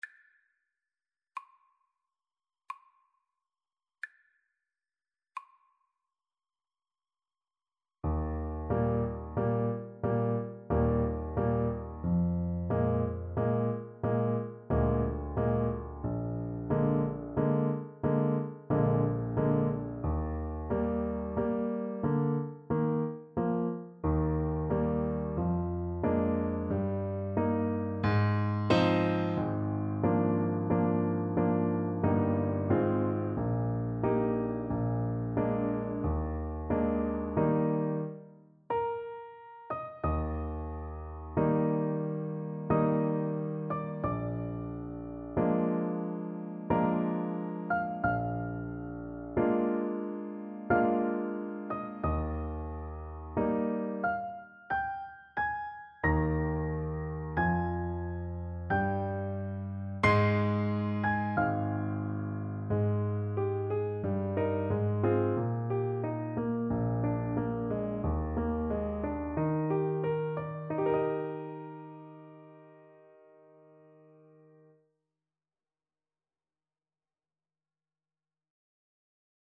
3/4 (View more 3/4 Music)
Adagio =45
Classical (View more Classical Clarinet Music)